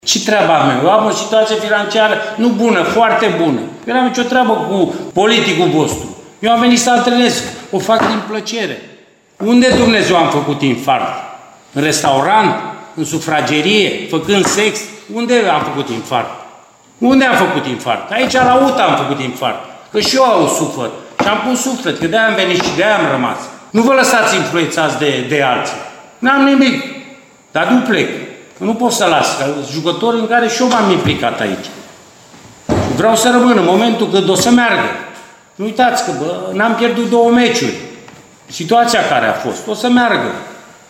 Sesiunea de declarații a lui Mircea Rednic înaintea deplasării UTA-ei la Petrolul Ploiești a fost o înlănțuire de replici pe ton ridicat pe mai multe subiecte.